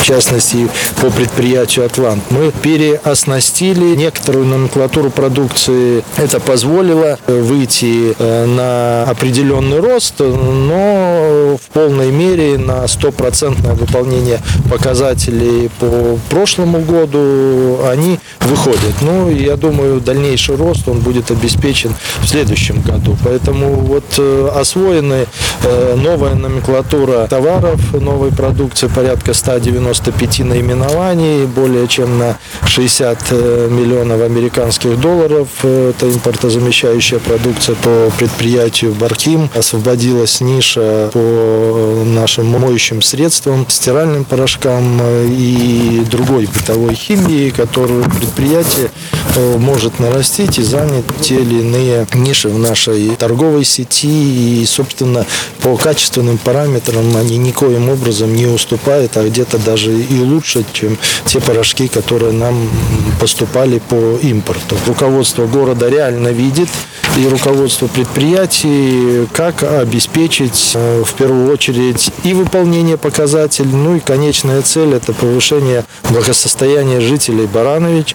Так, станкостроительному заводу удалось стабилизировать производство, и увеличить выпуск компрессоров для обеспечения возросшей потребности в них, отметил журналистам Игорь Петришенко.